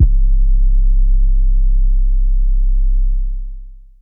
BRING OUT YOUR DEAD 808.wav